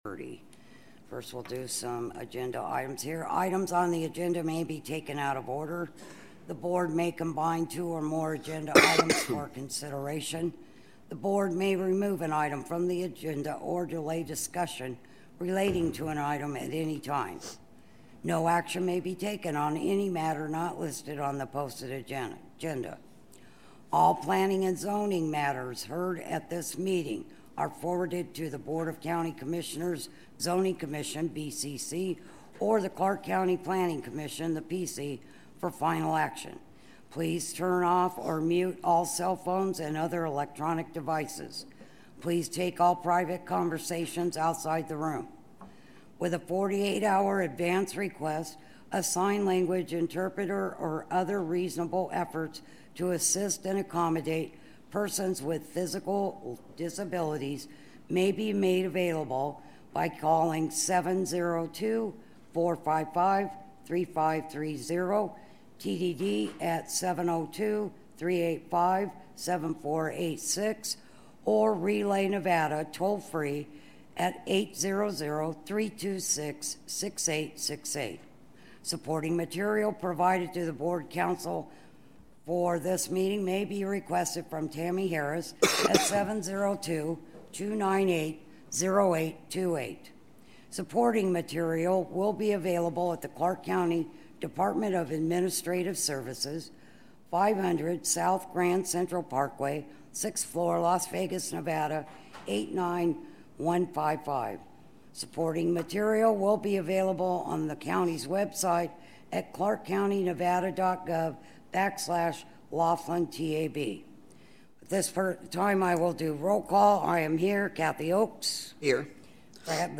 When: Meetings are held on the second (2nd) Tuesday of the month, and when there are zoning agenda items, the last Tuesday of the month, 1:30 p.m. Where: Town Hall, Laughlin Regional Government Center, 101 Laughlin Civic Dr., Laughlin, Nevada 89029